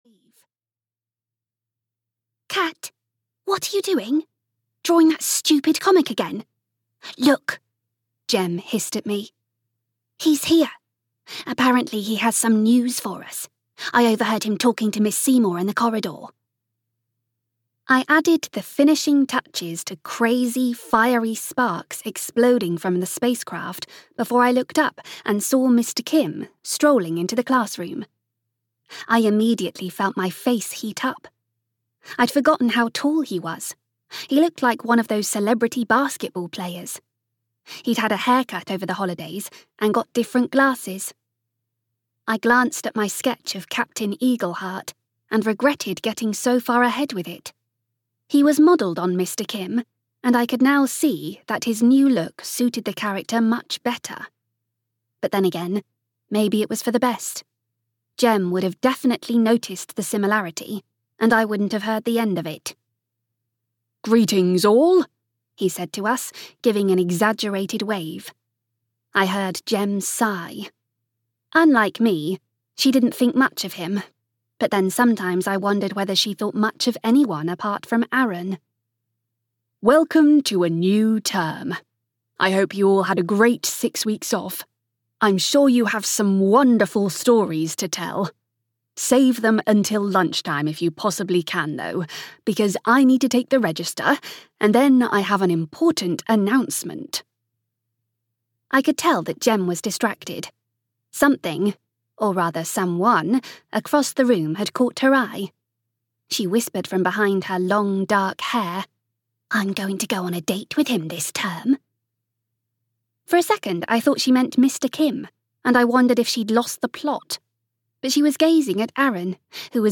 Audio knihaGirl 38: Finding a Friend (EN)
Ukázka z knihy